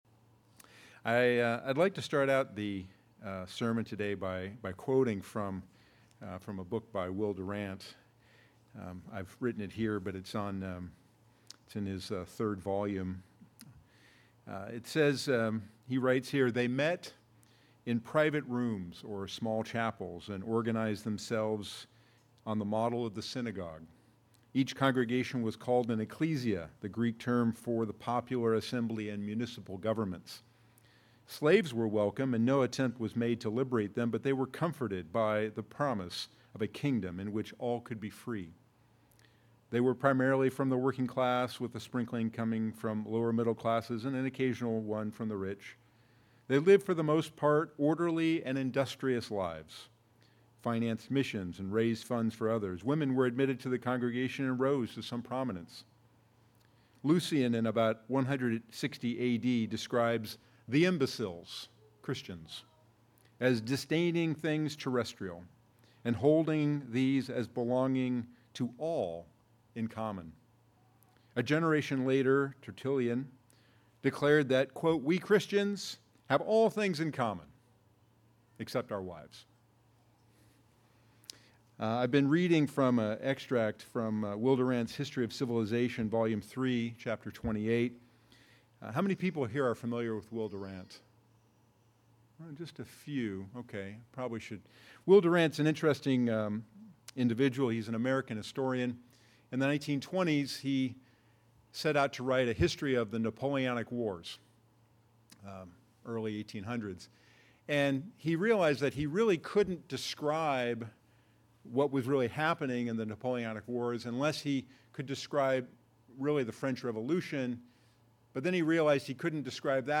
In Philippians 1:27, The Apostle Paul encouraged the Philippians to strive together for the faith of the gospel. In this sermon